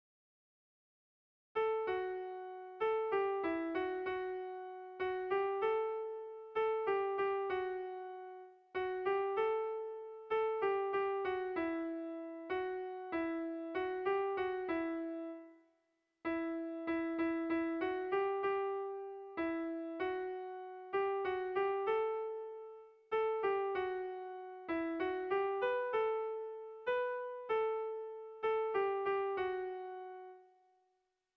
Gabonetakoa
Zortziko txikia (hg) / Lau puntuko txikia (ip)